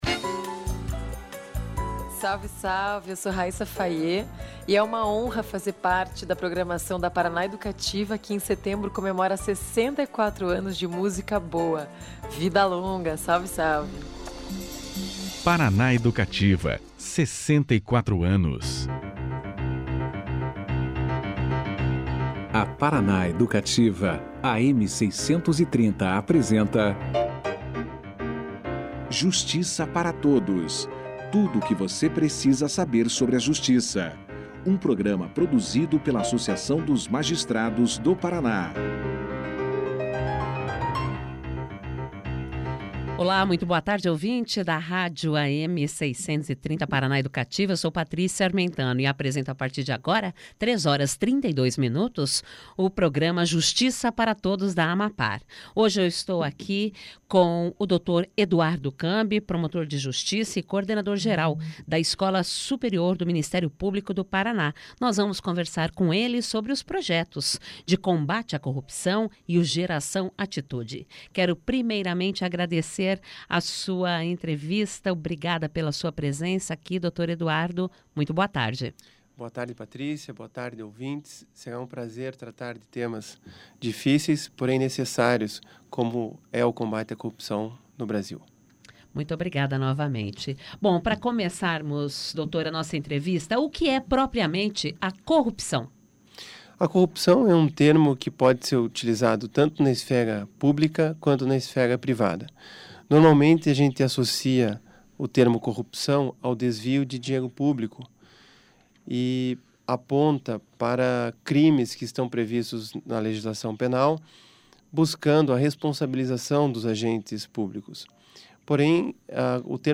Para falar sobre o assunto, o convidado foi o Promotor de Justiça do Ministério Público do Paraná, Eduardo Cambi, que fez uma reflexão sobre os casos de corrupção no país. Segundo o Promotor, a corrupção pode estar em cada pessoa da sociedade, não só na política ou nas grandes empresas.